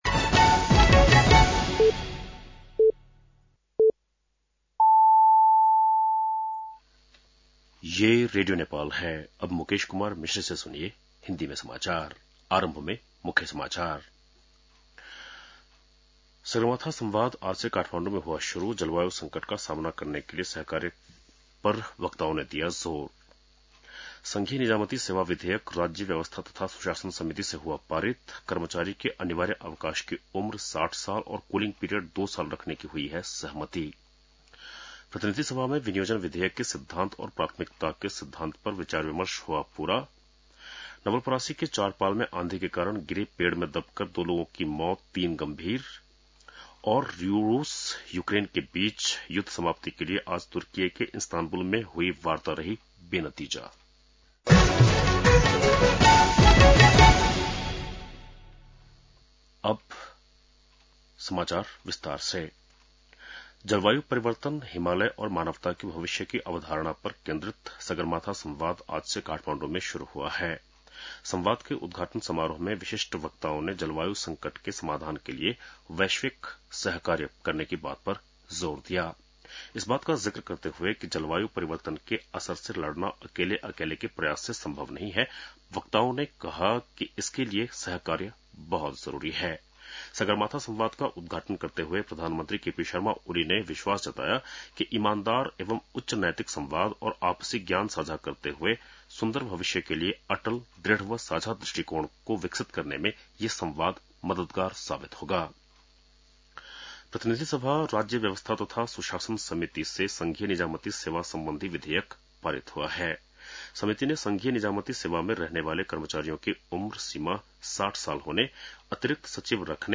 बेलुकी १० बजेको हिन्दी समाचार : २ जेठ , २०८२